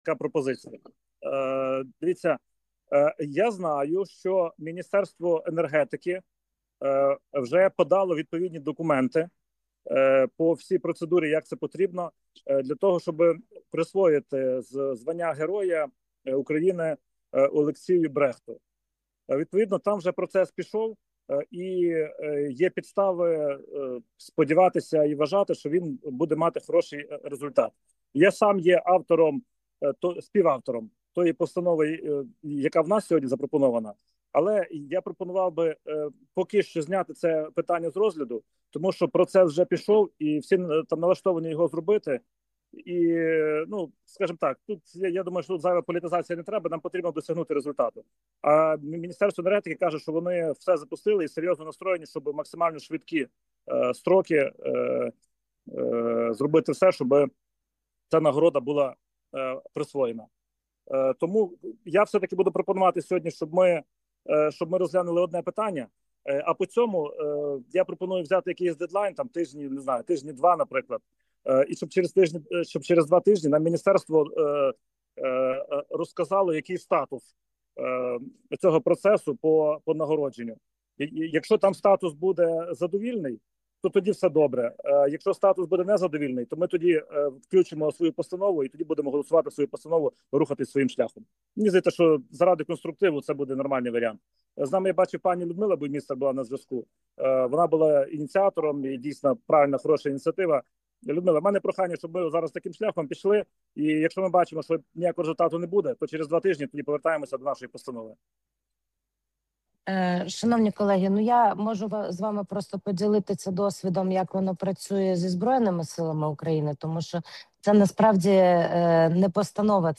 Засідання Комітету від 30.01.2026 року